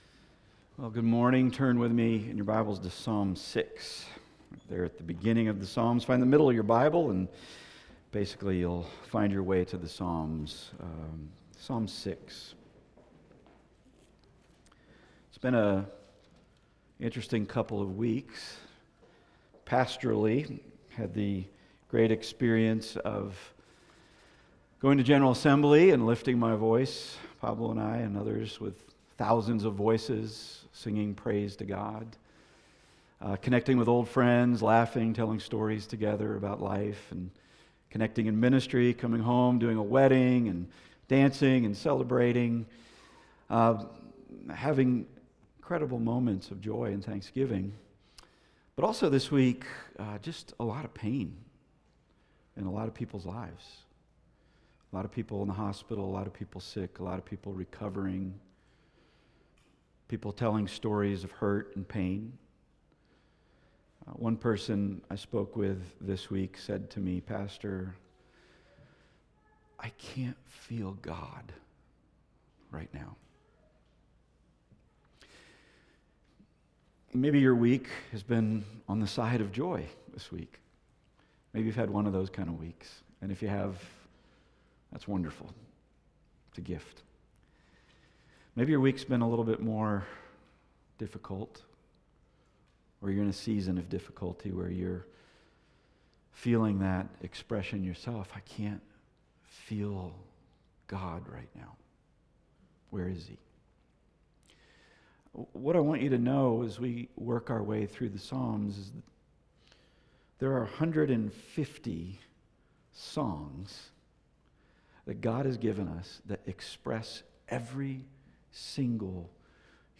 Passage: Psalm 6 Service Type: Weekly Sunday